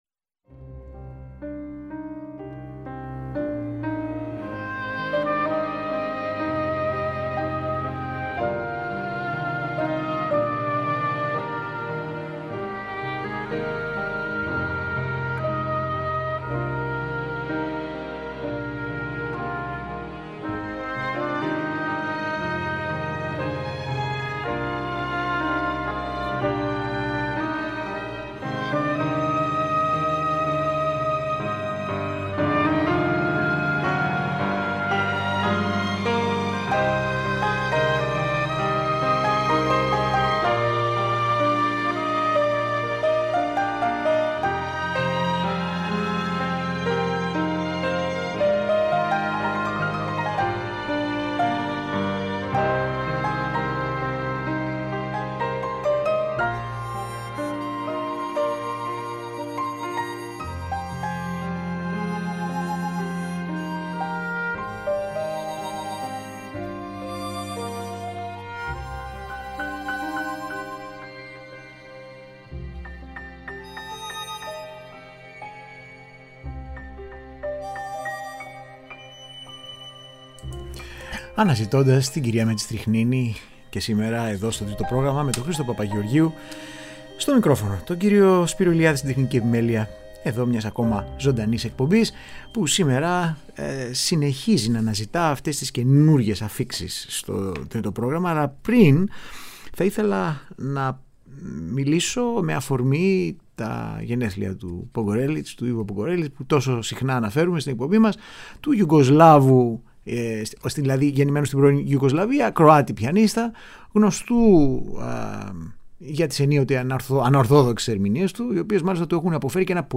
Από τις πρόσφατες αφίξεις στο Τρίτο Πρόγραμμα συνεχίζουμε να διαλέγουμε “ad hoc”, αποσπάσματα από ηχογραφήσεις που δημιουργούν εντύπωση, έκπληξη, ανατροπές και μια ευχάριστη ανανέωση στις προσλαμβάνουσες μουσικές μας εμπειρίες.